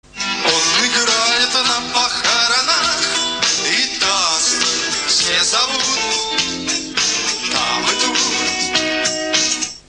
Он же, кстати, и поёт.